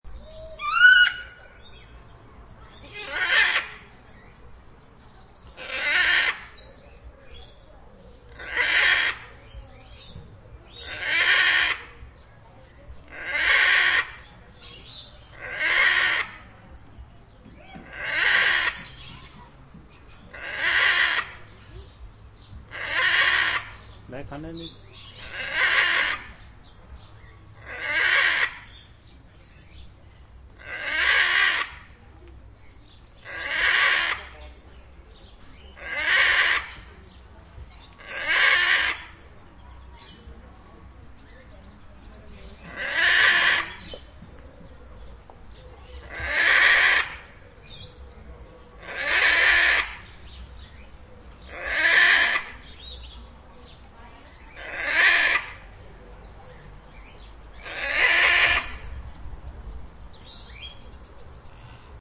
Guacamayo Azul y Amarillo (Ara ararauna)
• Comportamiento: Son aves sociales e inteligentes, capaces de imitar sonidos y palabras humanas.